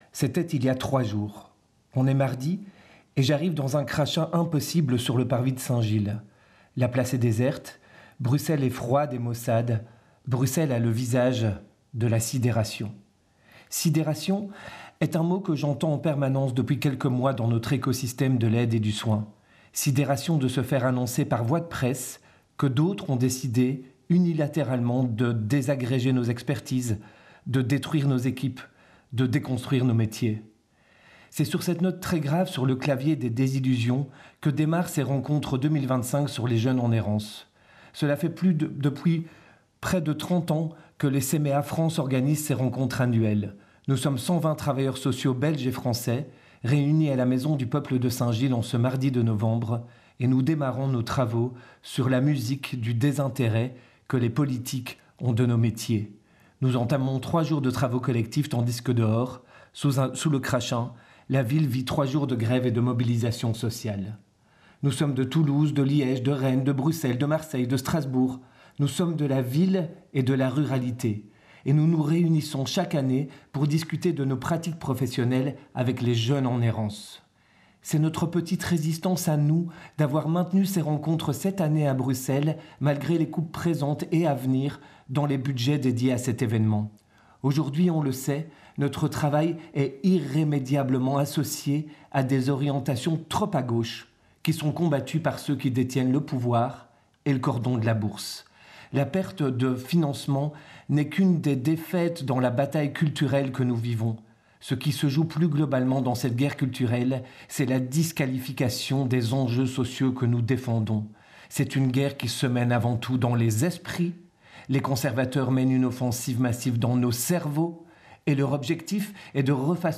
Matinée d'étude - Quel avenir pour les jeunes sans chez-soi ?